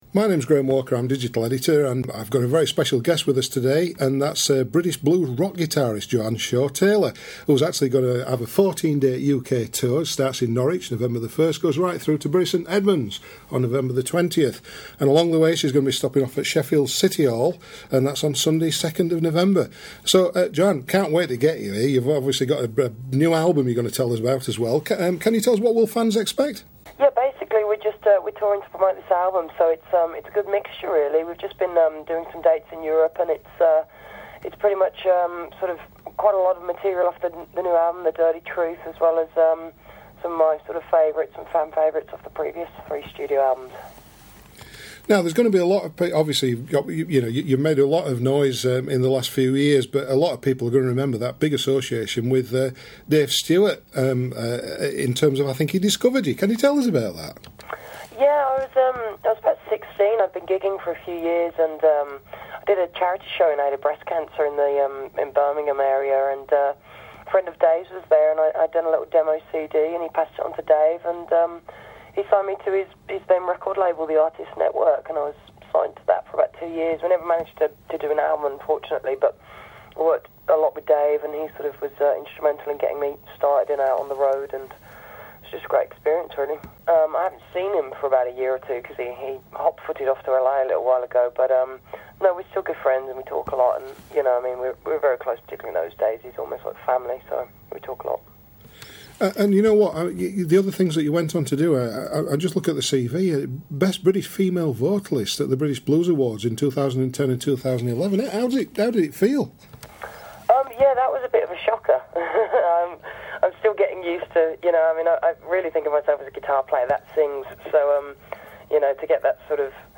INTERVIEW: Joanne Shaw Taylor